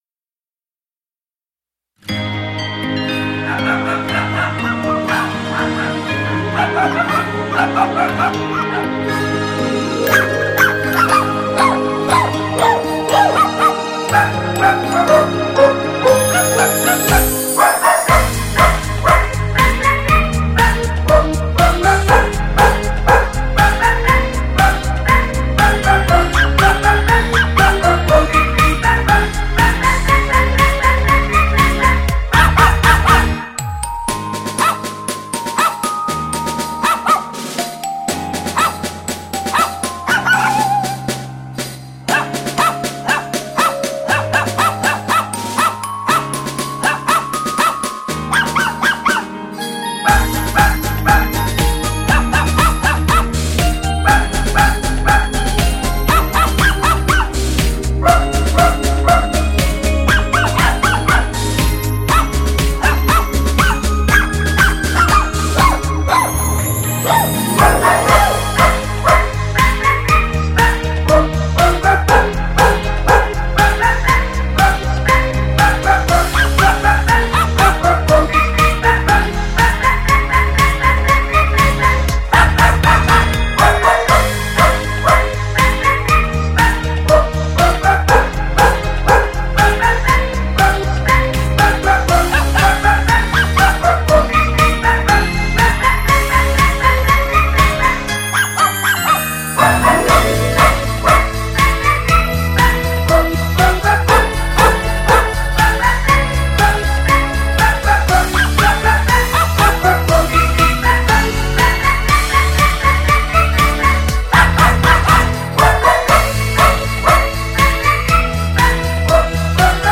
原本就快乐的旋律，重新编曲之后又加上狗狗们响亮跳跃的声音，新年气氛十足